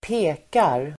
Uttal: [²p'e:kar]